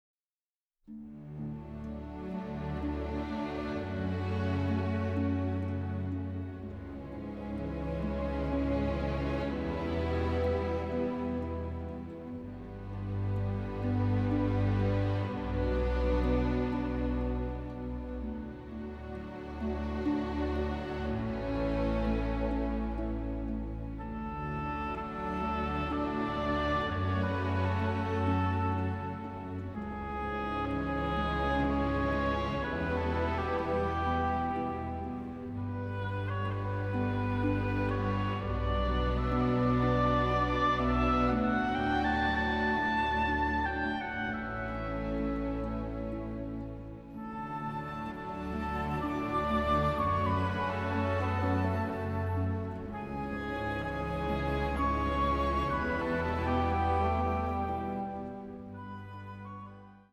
symphonic score
a sensitive, powerful, highly melodic score
from the original album stereo master tapes